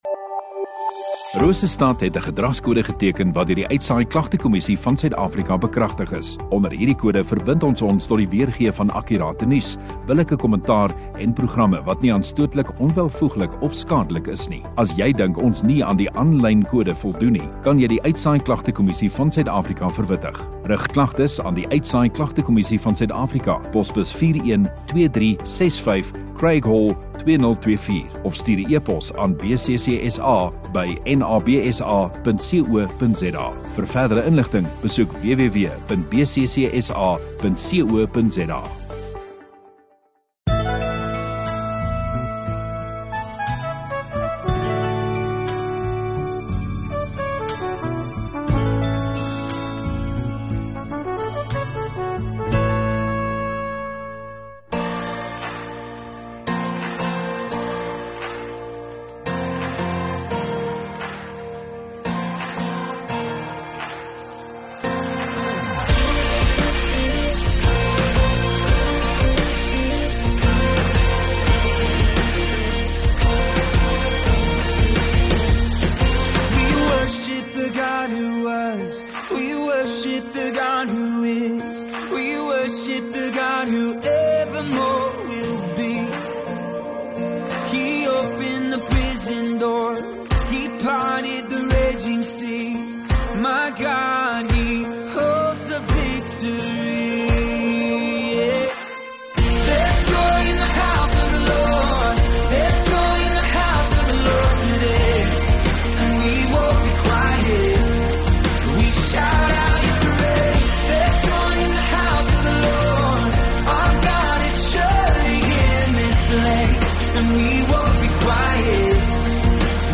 16 Dec GELOFTEDAG Maandagaand Erediens